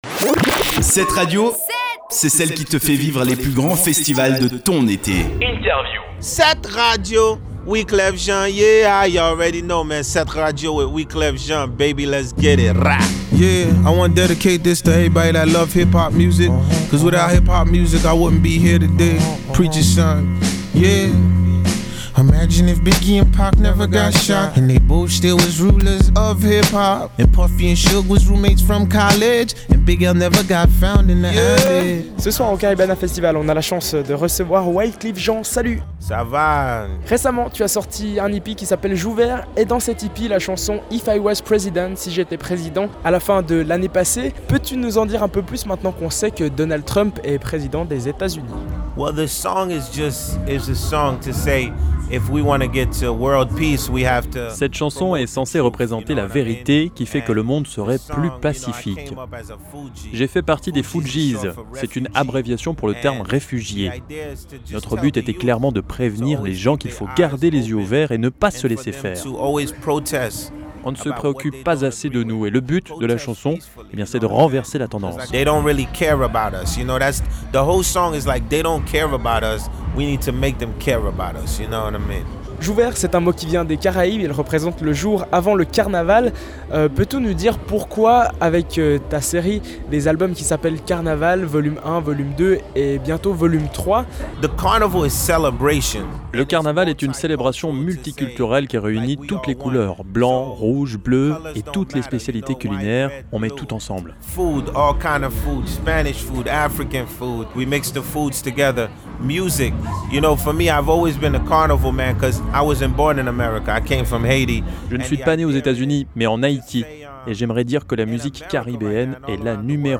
Wyclef Jean, Caribana Festival 2017
INTERVIEW-WYCLEF-JEAN.mp3